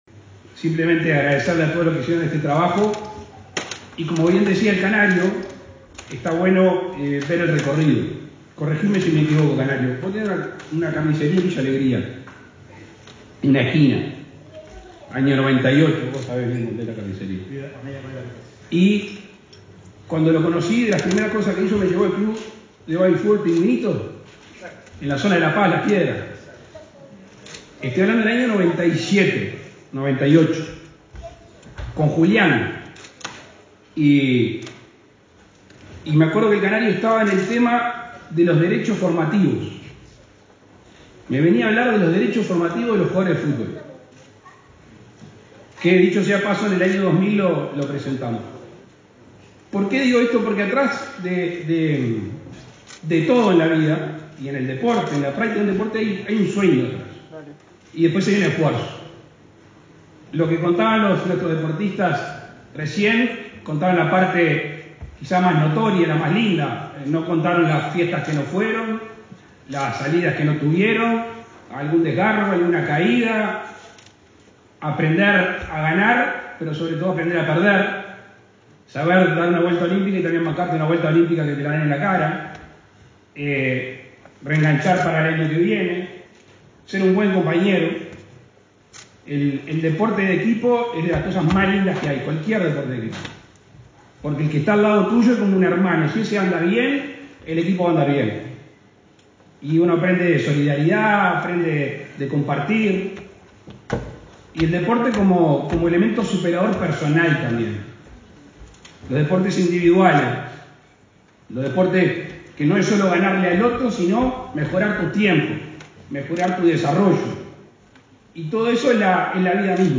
Palabras del presidente de la República, Luis Lacalle Pou
Este 19 de agosto, el mandatario participó en el lanzamiento de Organización Nacional de Deporte Infantil, en la ciudad de Trinidad, Flores.